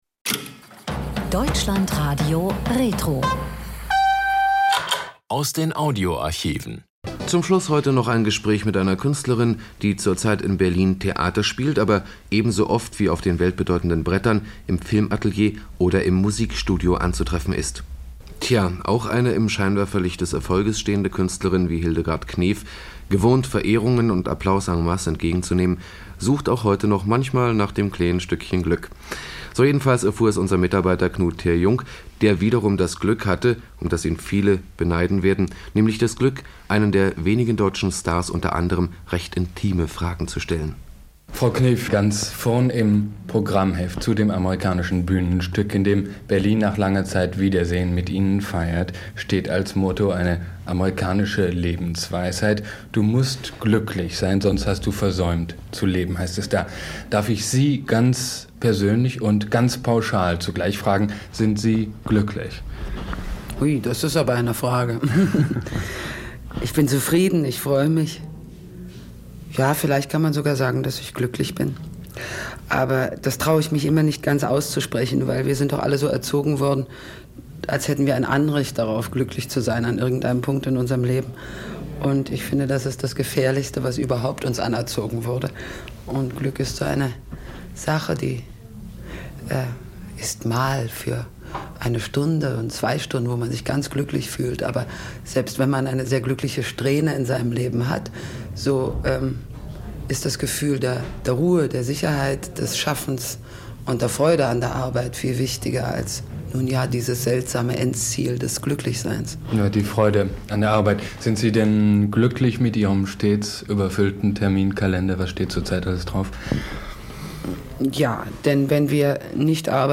Hildegard Knef im RIAS-Gespräch